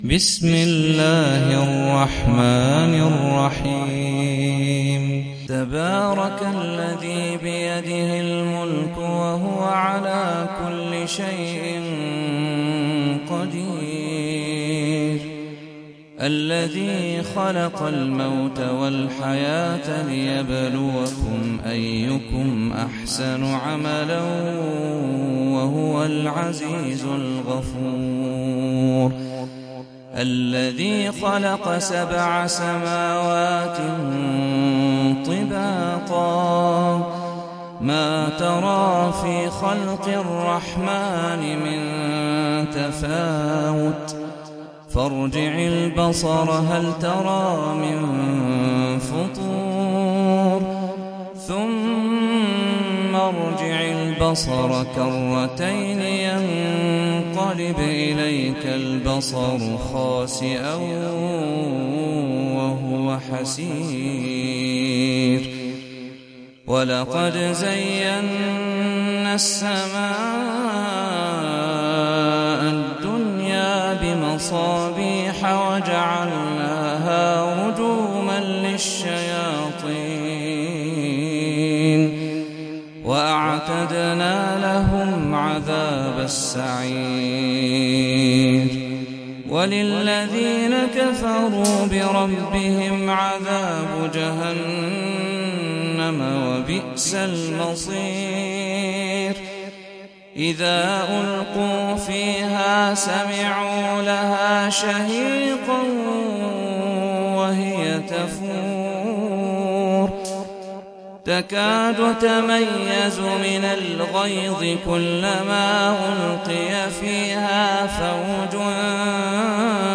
سورة الملك - صلاة التراويح 1446 هـ (برواية حفص عن عاصم)
جودة عالية